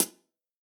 UHH_ElectroHatD_Hit-20.wav